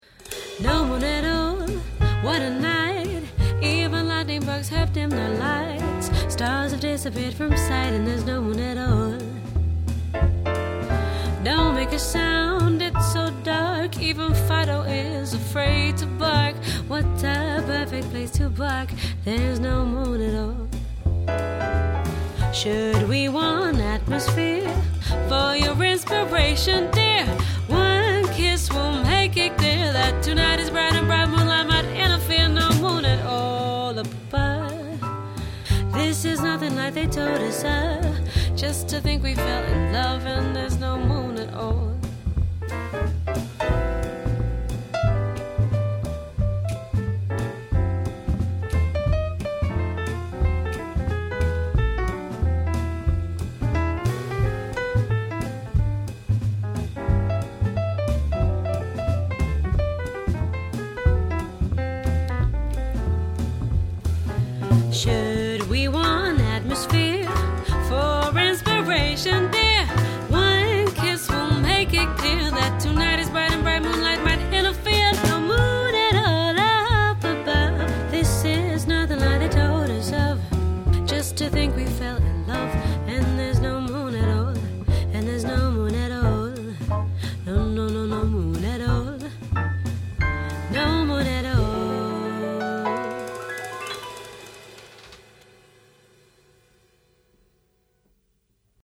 ultra-smooth jazz & modern repertoire
Female Fronted Jazz Quartet Based In London